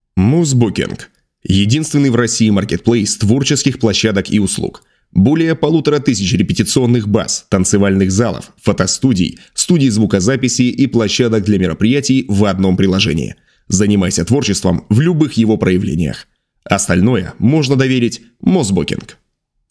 Муж, Рекламный ролик/Зрелый